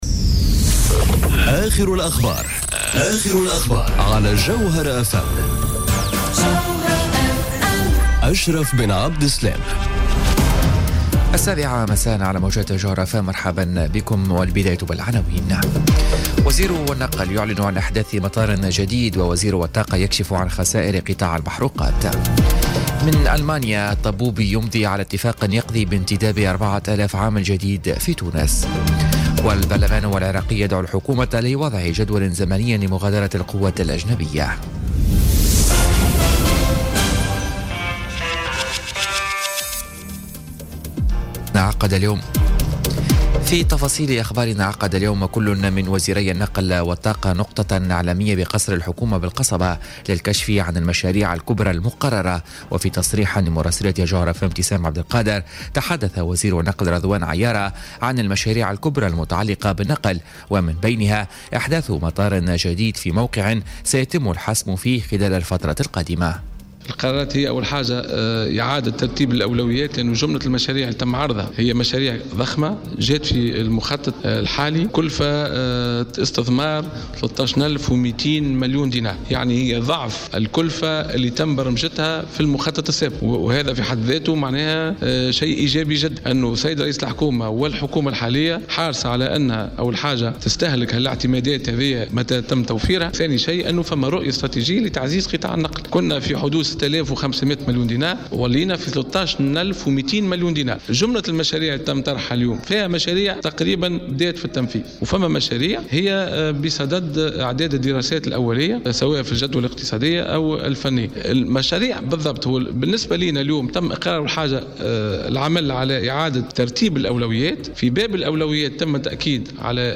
نشرة أخبار السابعة مساءً ليوم الخميس غرة مارس 2018